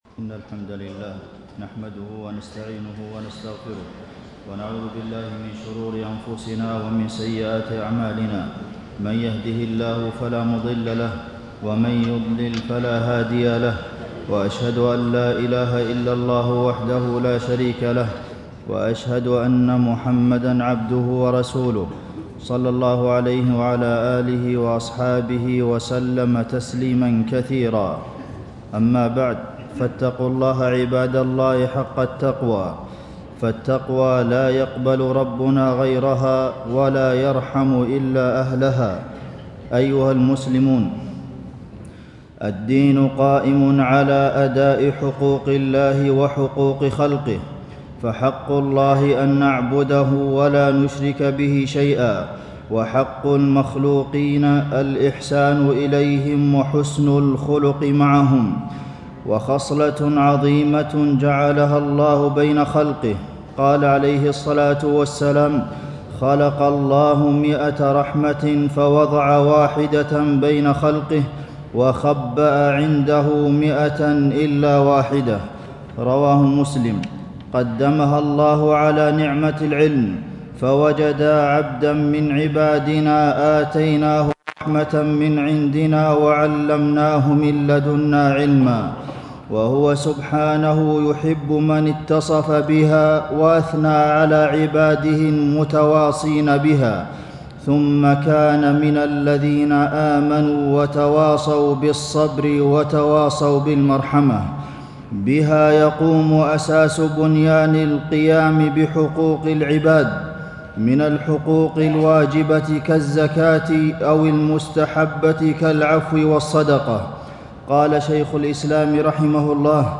تاريخ النشر ٢٩ جمادى الأولى ١٤٣٦ هـ المكان: المسجد النبوي الشيخ: فضيلة الشيخ د. عبدالمحسن بن محمد القاسم فضيلة الشيخ د. عبدالمحسن بن محمد القاسم الرحمة والشفقة في الإسلام